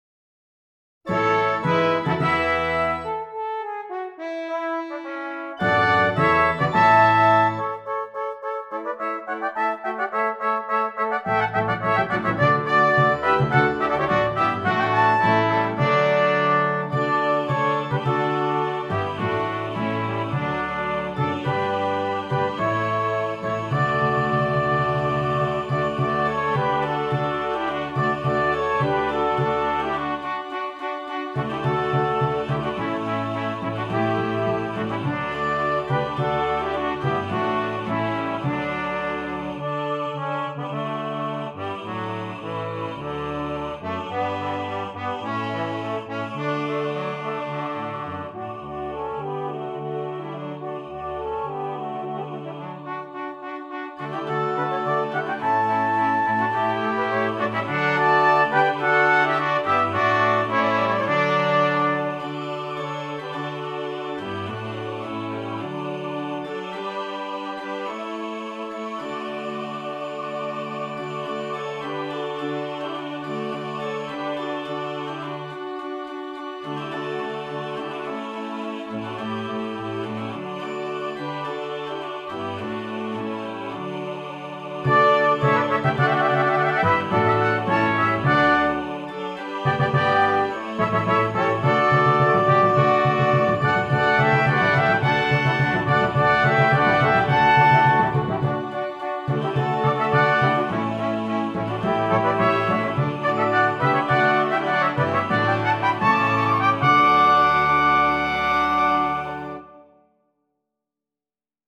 Christmas
Brass Quintet, Organ and Optional Choir